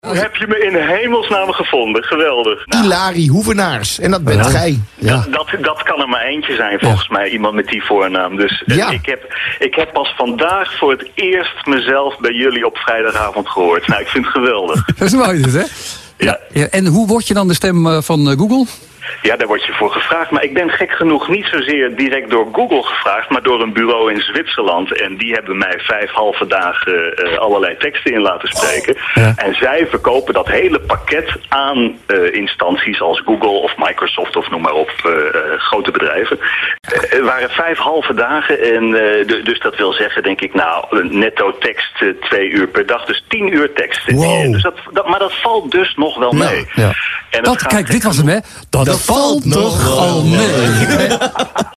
Male
Adult (30-50)
Natural Speak